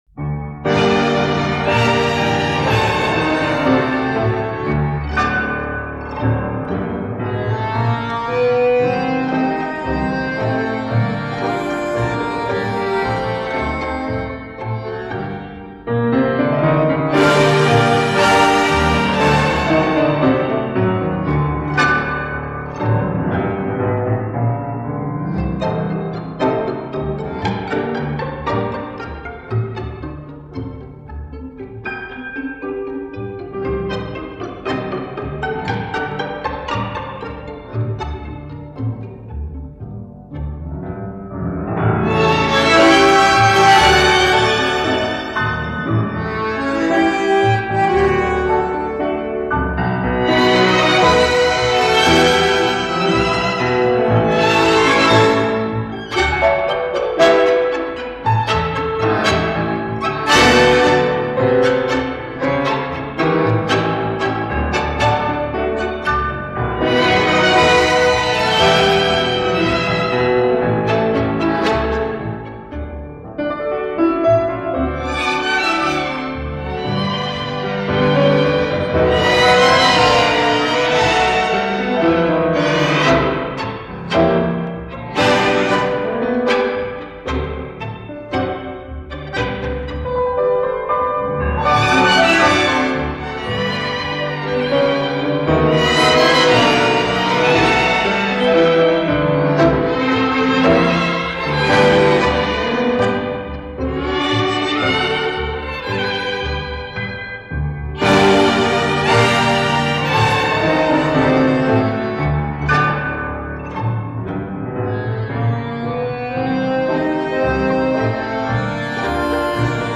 Танго